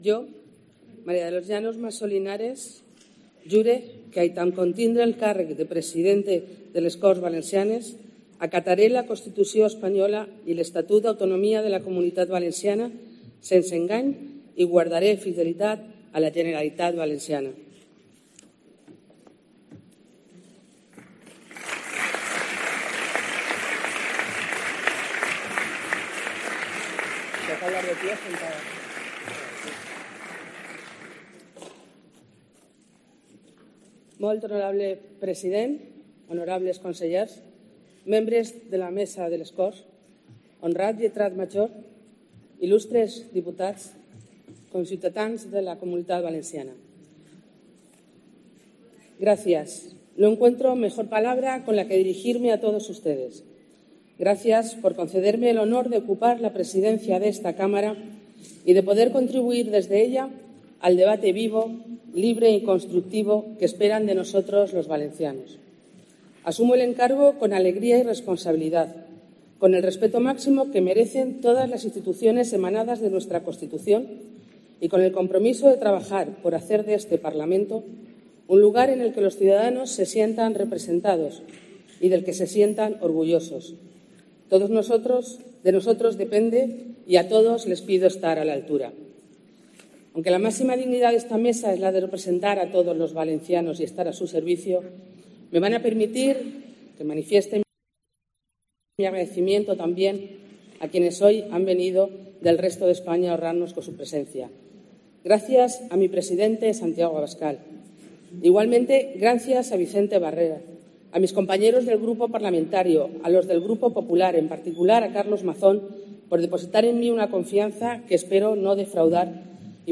Primer discurso de Llanos Massó (VOX) como presidenta de las Cortes Valencianas